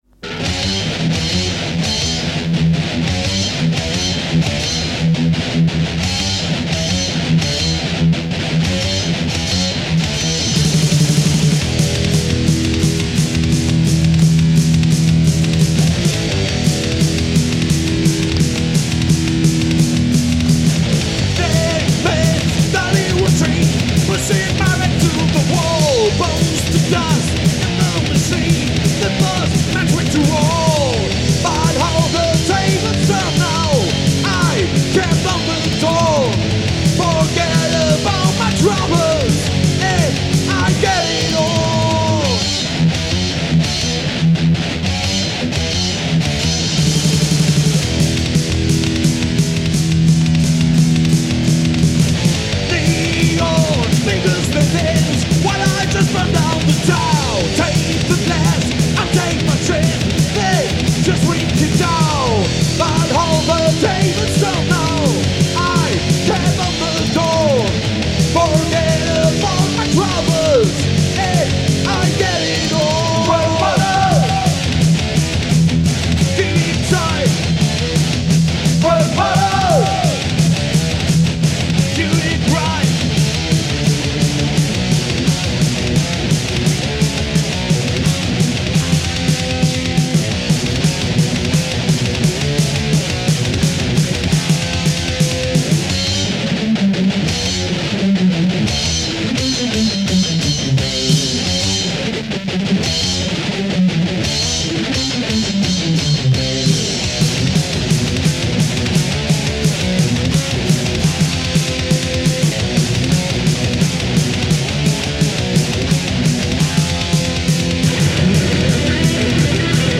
Eigenproduktion nur für Promotionzwecke
Gitarre, Keyboards
Schlagzeug